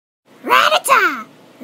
PLAY Pokemon Rattata Cry